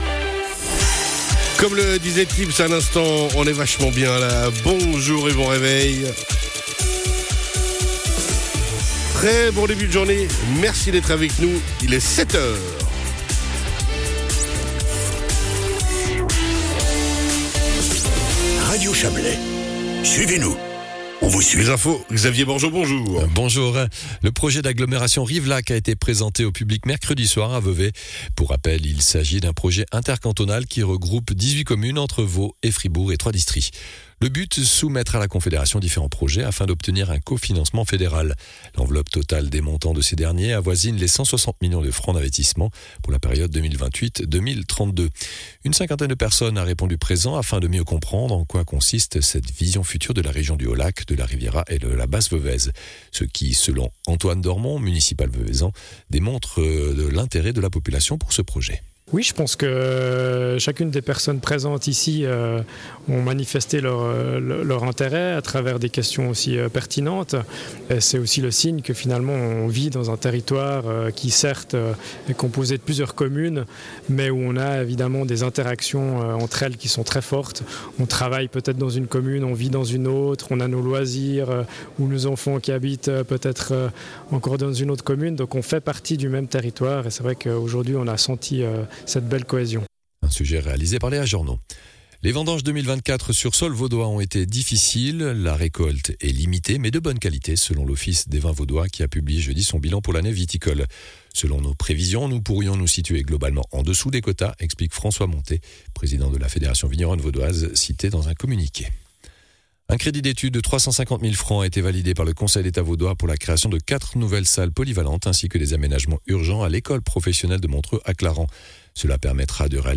Le journal de 7h00 du 01.11.2024